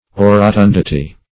Orotundity \O`ro*tun"di*ty\, n.